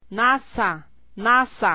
Tabla I: Alfabeto Oficial sonorizado
Nasales m